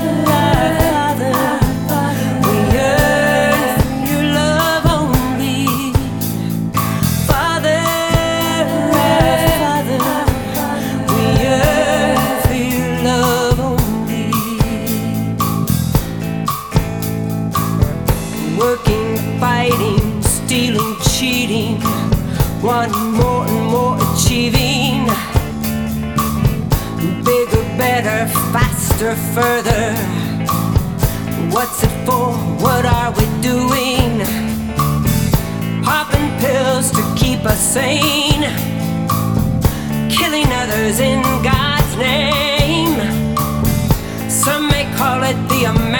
PREVIEW Songs [Rock Genre]